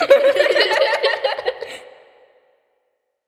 Vox (Metro Laugh)_2(1).wav